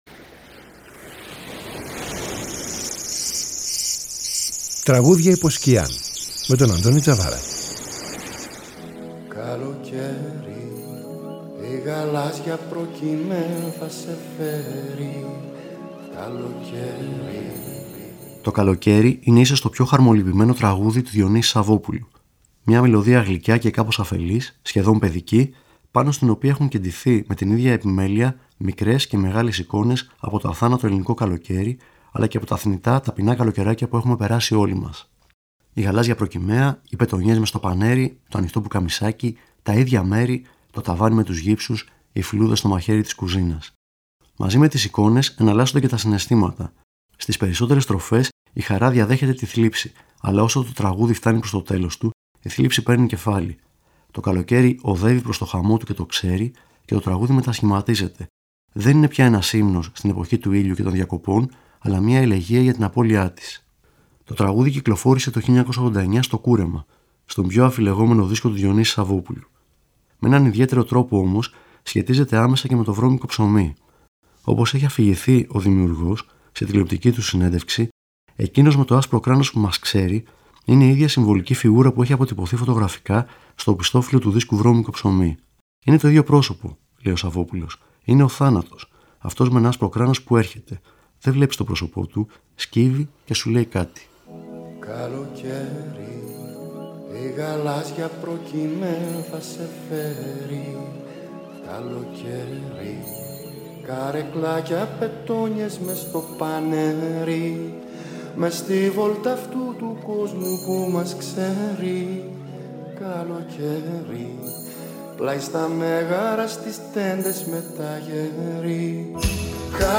Μια μελωδία γλυκιά και κάπως αφελής, σχεδόν παιδική, πάνω στην οποία έχουν κεντηθεί με την ίδια επιμέλεια μικρές και μεγάλες εικόνες από το αθάνατο ελληνικό καλοκαίρι αλλά και από τα θνητά ταπεινά καλοκαιράκια που έχουμε περάσει όλοι μας.